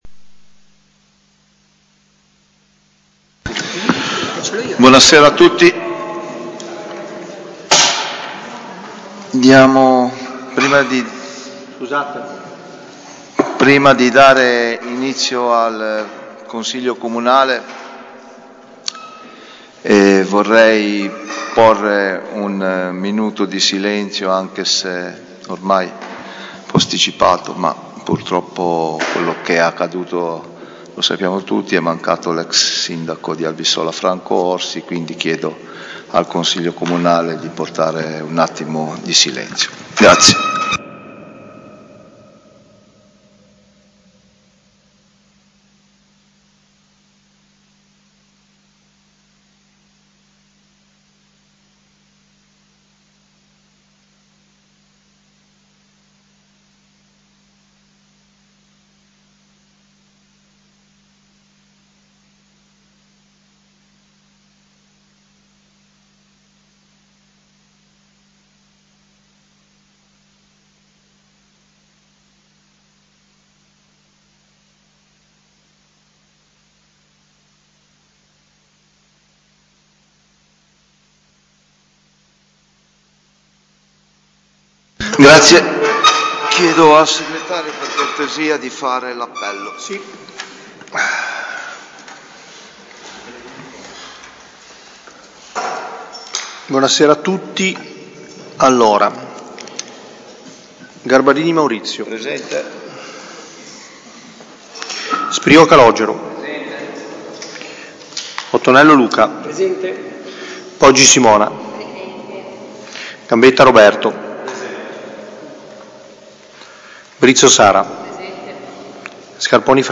Consiglio comunale del 28 novembre 2025 - Comune di Albisola Superiore
Seduta del Consiglio comunale venerdì 28 novembre 2025, alle 21.00, presso l'Auditorium comunale in via alla Massa. In seduta straordinaria sono stati trattati i seguenti argomenti: Addizionale IRPEF - Conferma aliquota per l'anno 2026. Imposta Municipale Propria (IMU) - Approvazione aliquote anno 2026.